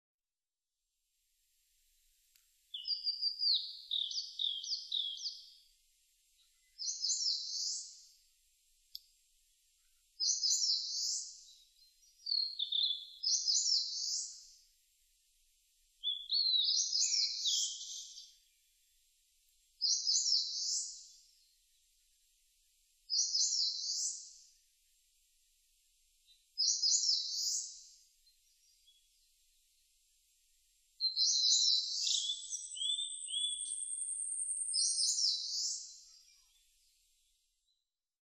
センダイムシクイ　Phylloscopus coronatusウグイス科
日光市稲荷川中流　alt=730m  HiFi --------------
Windows Media Audio FILE MPEG Audio Layer3 FILE  Rec.: EDIROL R-09
Mic.: built-in Mic.
他の自然音：　 オオルリ・ヤブサメ・ホオジロ・ヒヨドリ・ウグイス・ゴジュウカラ